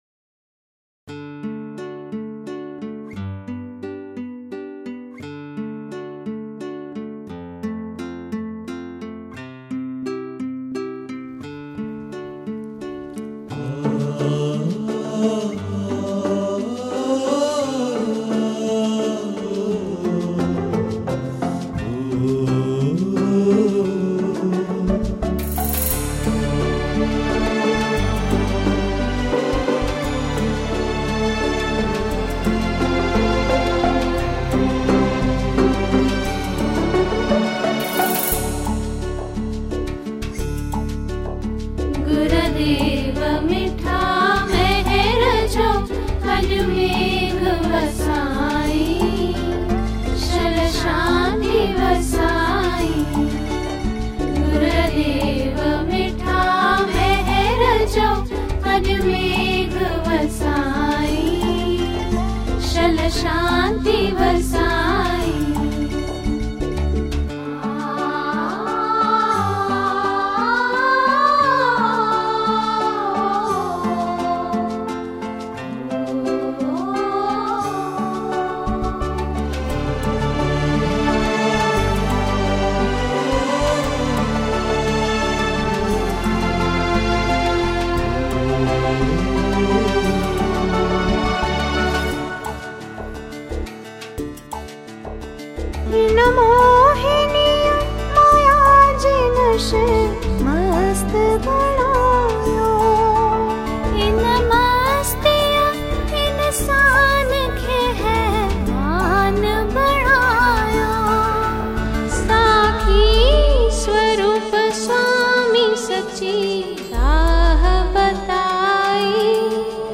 Sindhi Devotional songs